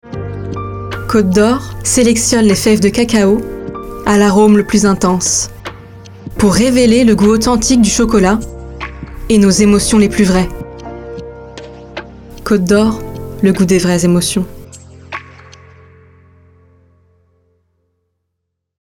Voix 18 - 30 ans